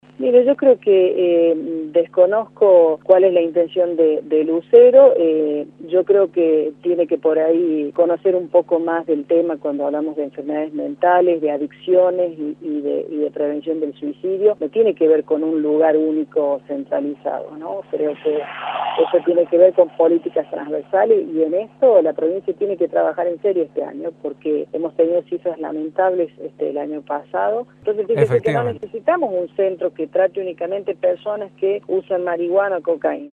Judit Díaz Bazán, diputada provincial, por Radio La Red